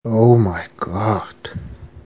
Download Oh My God Meme sound effect for free.